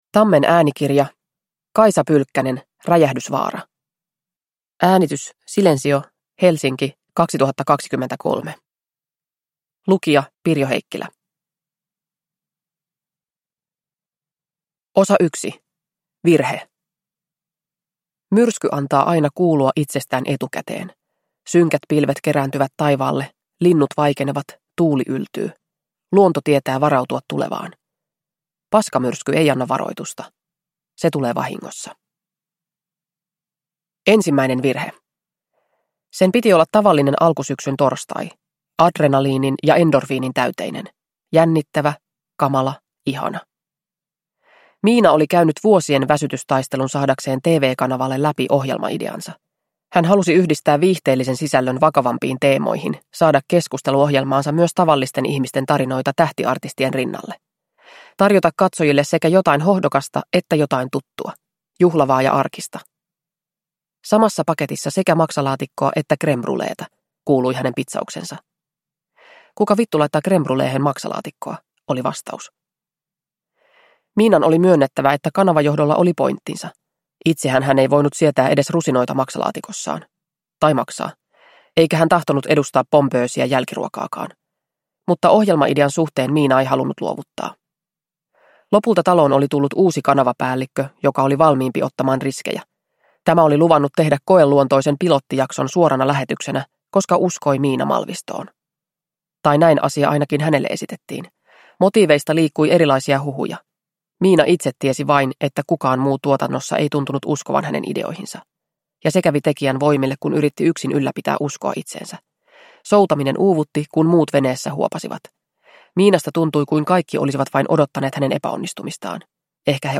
Räjähdysvaara – Ljudbok – Laddas ner